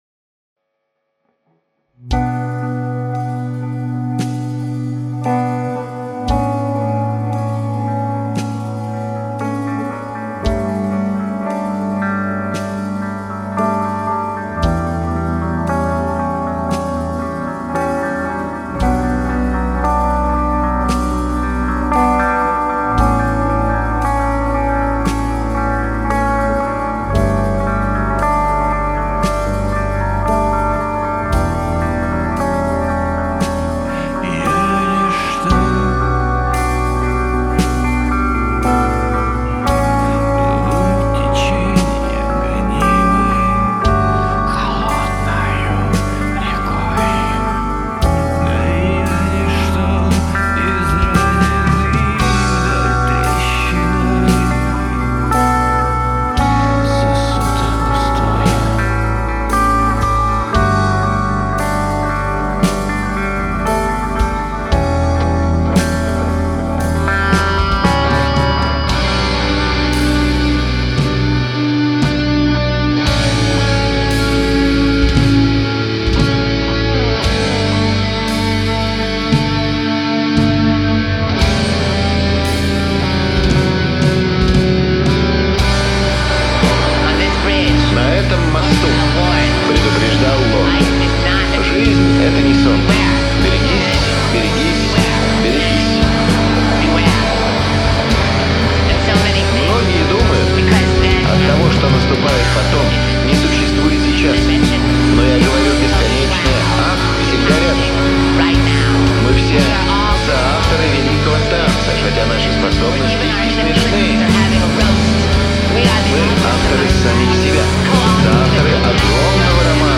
вокал, гитара
бас
ударные